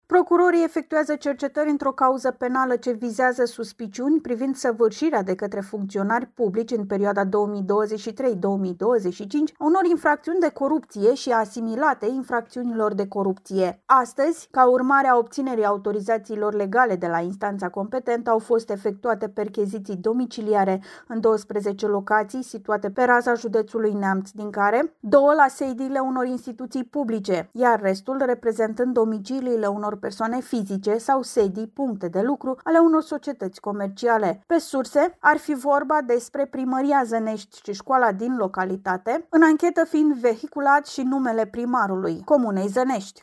Are detalii corespondenta noastră